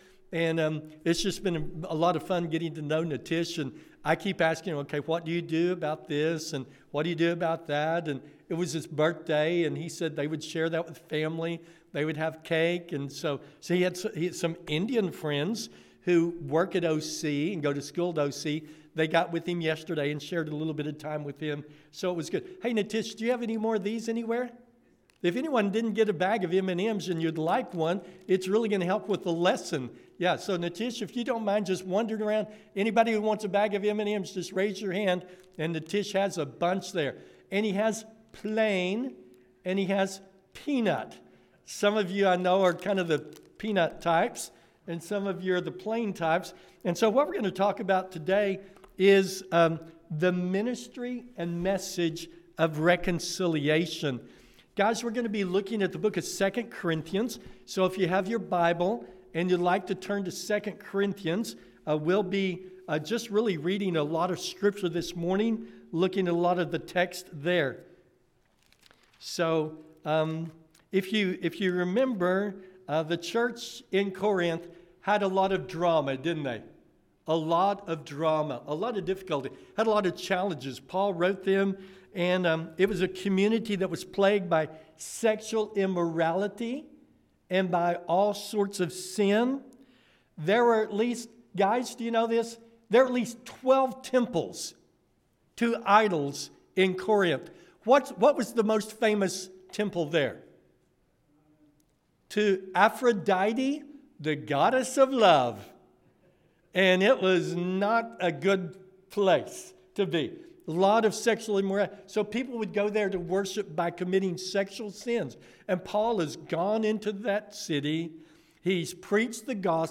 The M and M’s of Reconciliation – Sermon